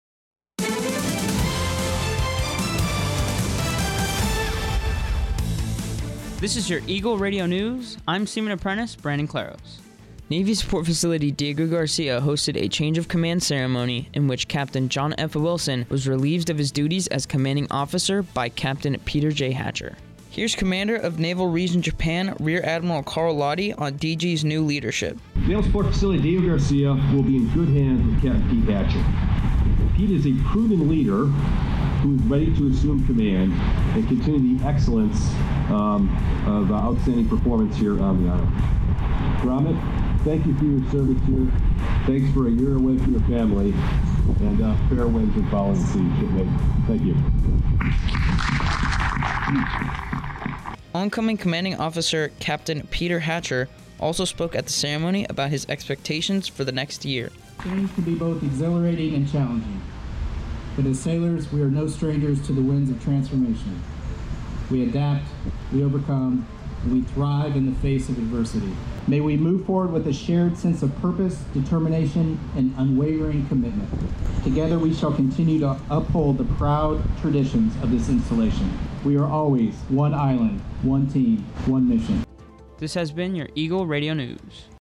Eagle Radio News is the American Forces Network Diego Garcia’s official radio newscast. It is produced by U.S. Navy Sailors stationed onboard Navy Support Facility Diego Garcia.